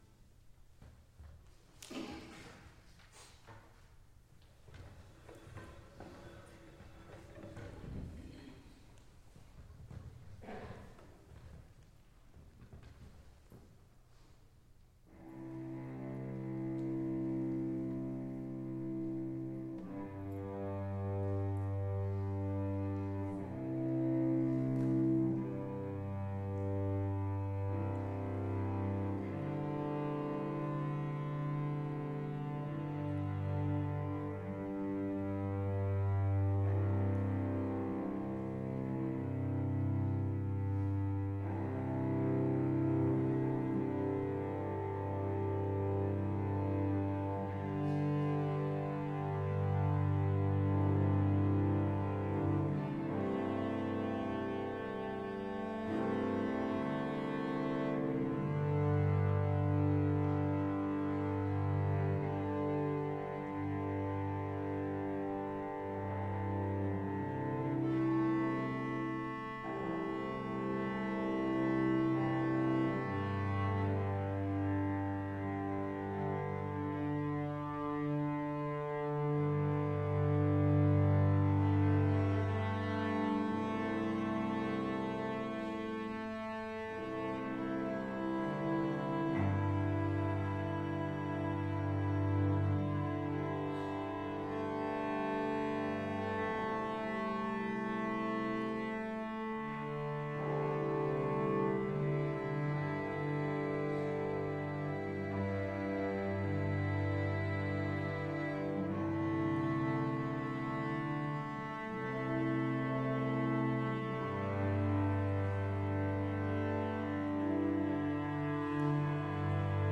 Live performance February 6, 2018, at Texas State University Performing Arts Center:
91839-rainbow-rising-live.mp3